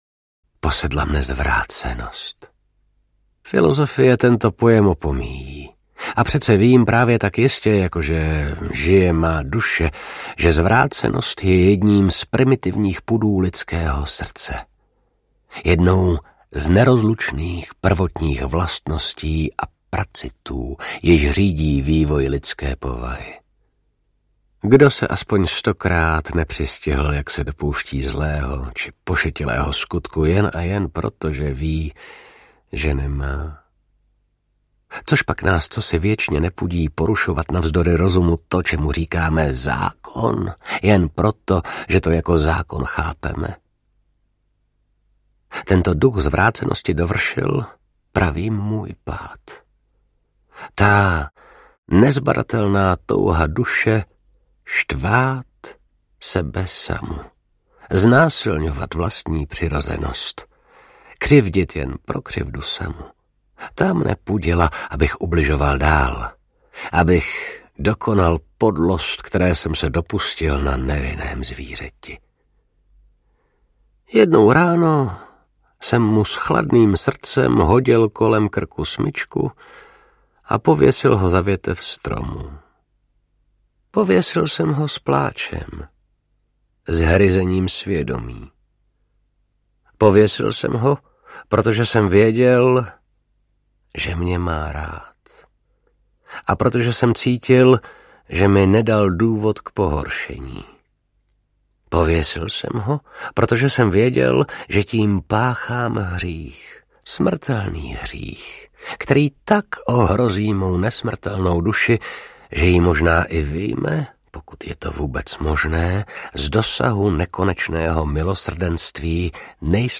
Audiokniha, kterou napsal Edgar Allan Poe.
Ukázka z knihy
| Vyrobilo studio Soundguru.